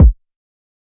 Metro Hard Kick.wav